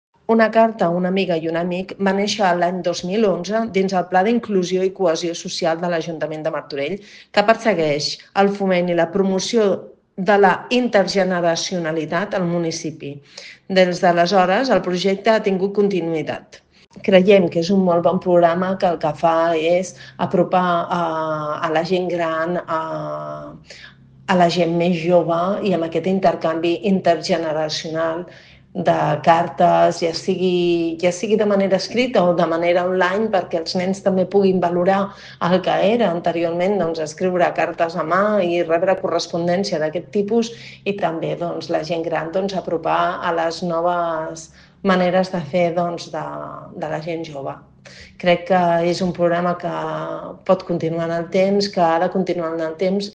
Montserrat Salas, regidora de Gent Gran de l'Ajuntament de Martorell